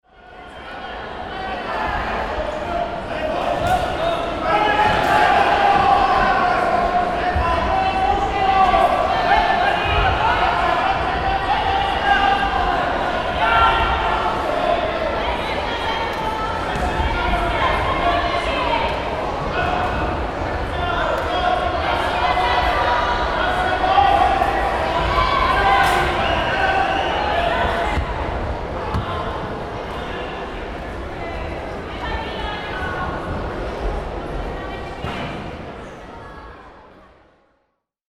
На этой странице собраны звуки, связанные с дзюдо: крики соперников, шум татами, команды тренера.
Атмосферный звук дзюдо-турнира (дети в схватке)